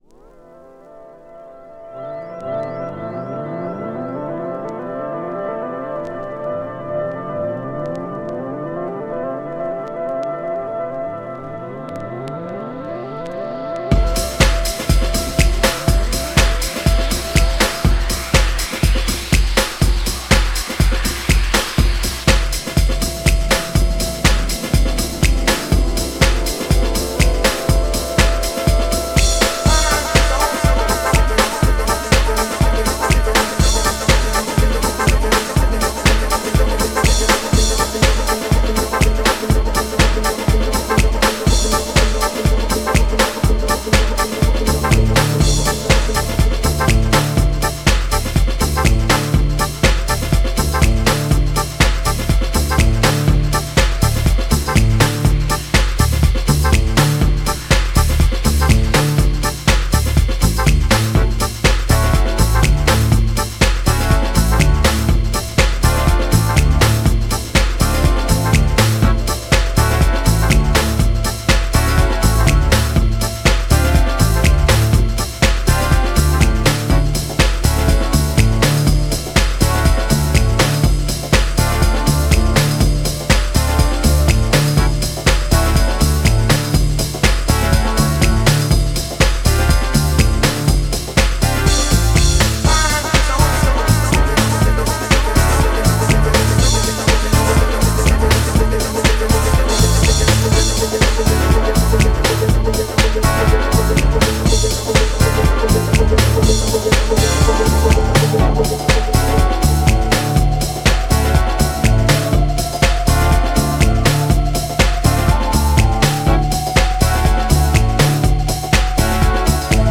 HOUSE REMIXも人気!
GENRE R&B
BPM 96〜100BPM